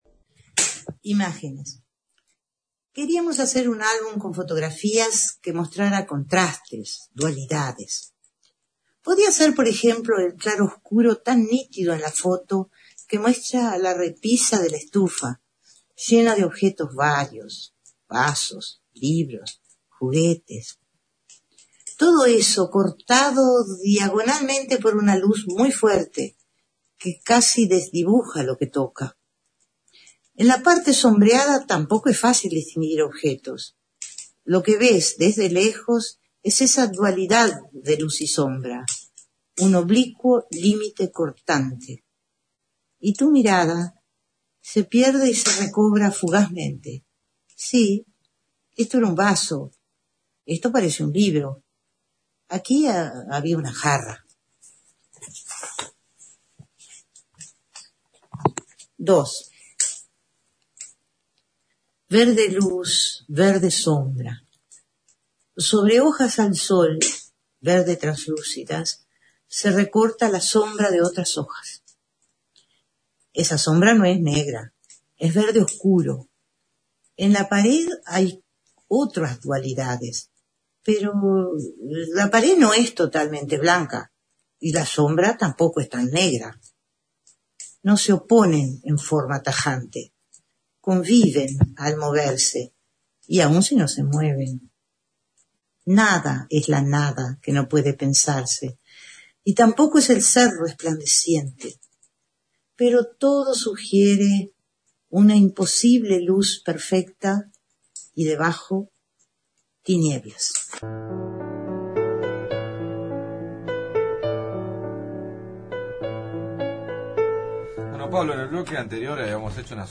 Compartimos una entrevista rescatada de Sopa de Letras (2006)
Rescatamos una entrevista a Circe Maia, realizada el 21 de noviembre del 2006 en el programa Sopa de Letras, donde le preguntamos a Circe si se consideraba platónica o aristotélica.